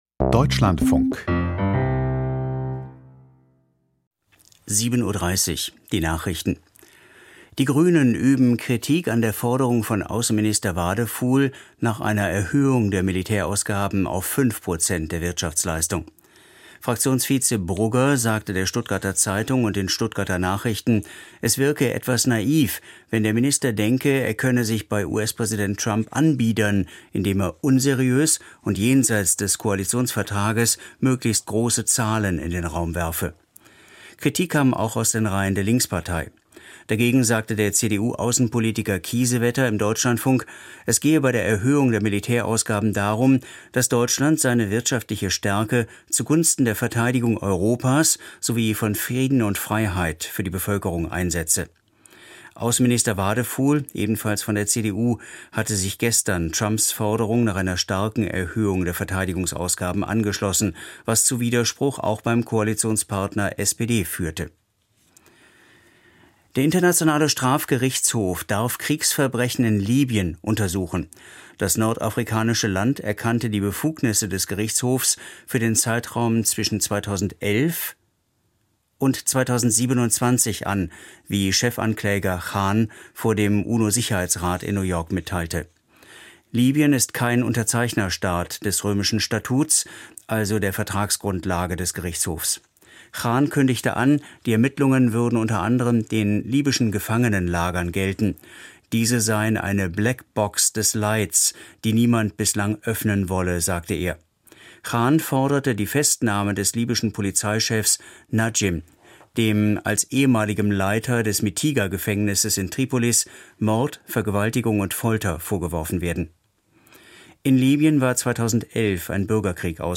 Hitzeresilienz: Interview mit Diana Pretzell, Grüne, 1. Bürgermeisterin Mannheim - 12.06.2025